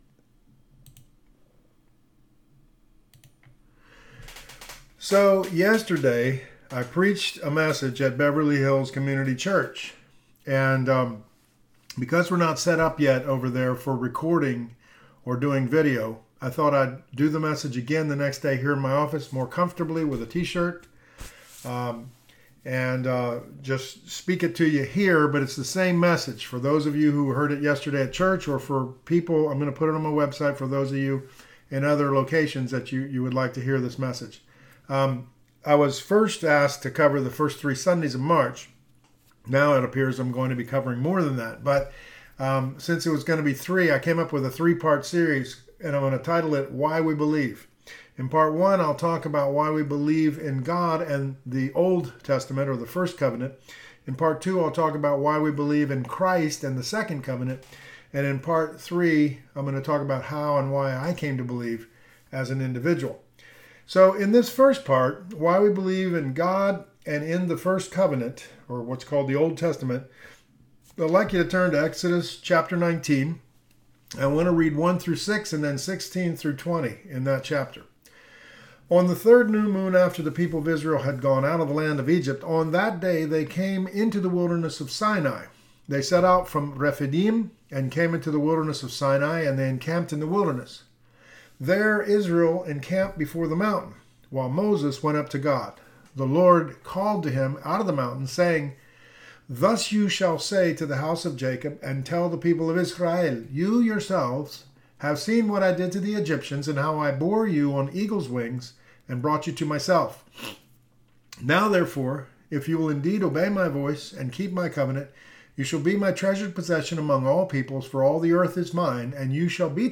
I’m “re-preaching” it here on the website, because we’re not set up yet to record at church.